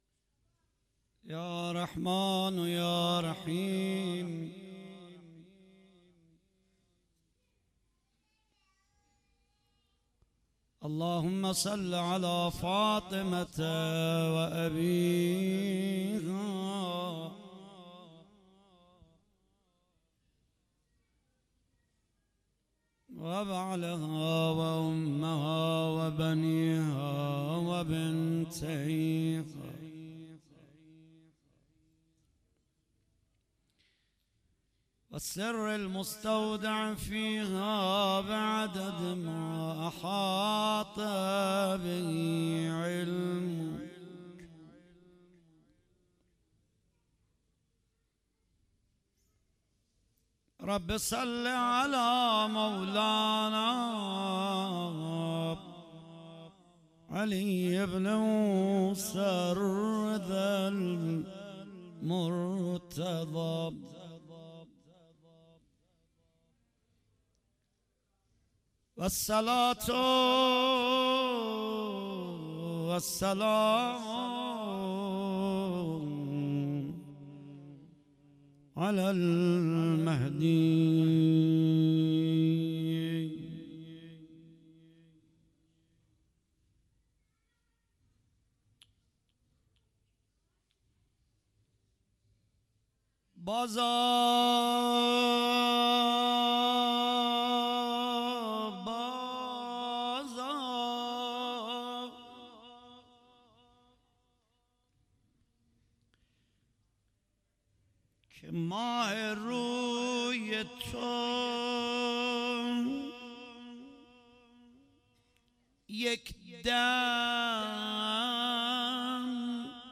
شب چهارم محرم 97 - روضه